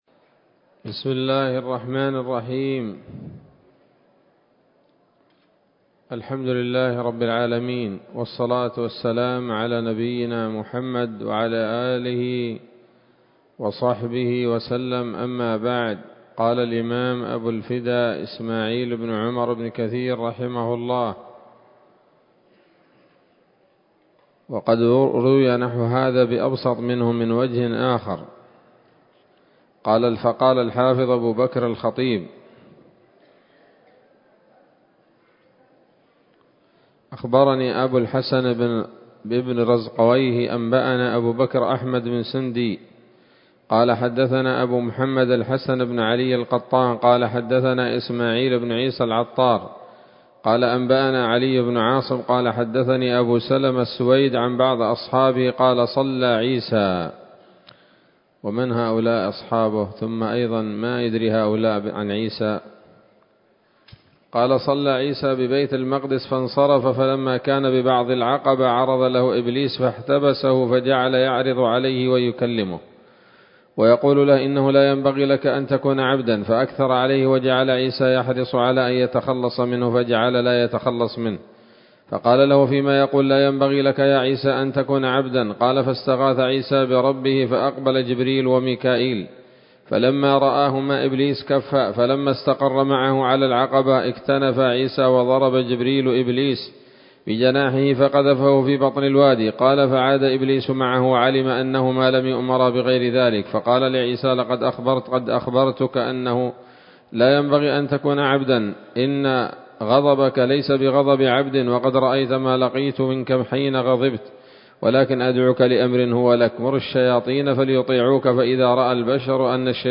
‌‌الدرس السابع والأربعون بعد المائة من قصص الأنبياء لابن كثير رحمه الله تعالى